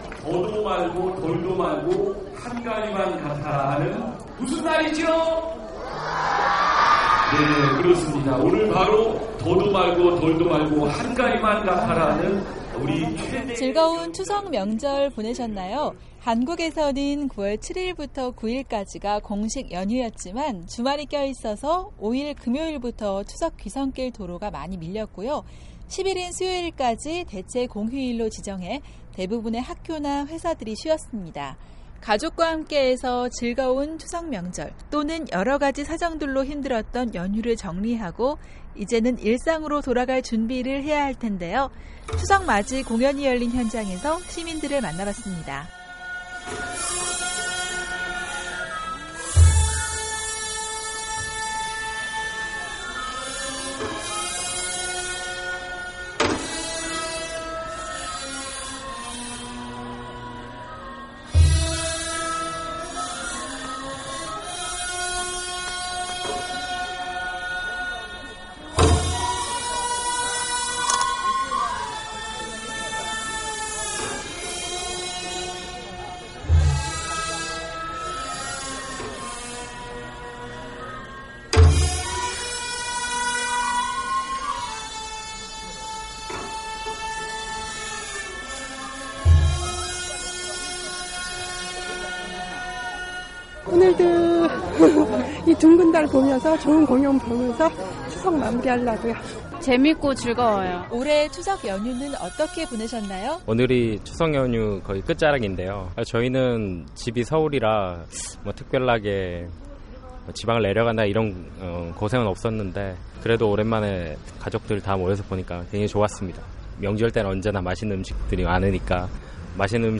한국사회의 이모저모를 전해드리는 ‘안녕하세요 서울입니다’ 순서, 한국 최대의 명절 추석 연휴가 모두 끝났습니다. 추석 연휴를 보내고 난 서울시민들의 목소리를